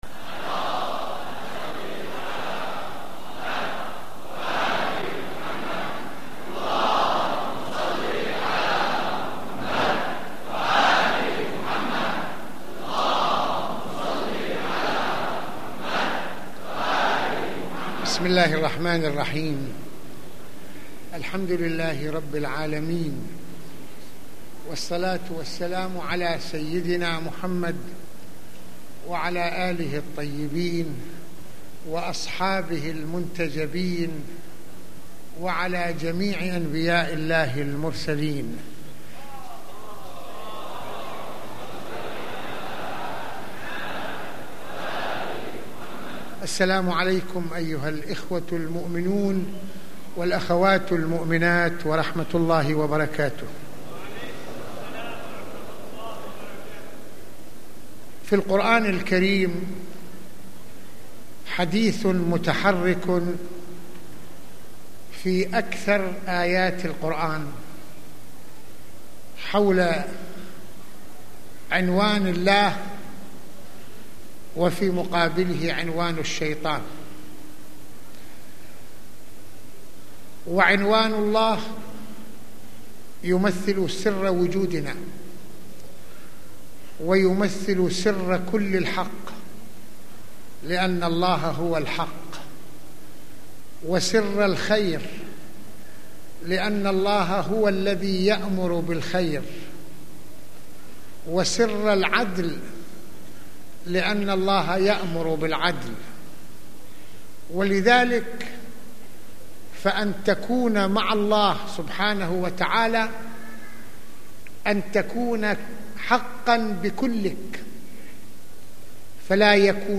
- المناسبة : موعظة ليلة الجمعة المكان : مسجد الإمامين الحسنين (ع) المدة : 30د | 01ث المواضيع : منطق الشيطان واتباعه - السياسيون وصناعة الكذب - اهل الايمان والثبات في ساحة الصراع مع الشيطان - ان ندرس عاشوراء ومواقف الاكثرية والاقلية.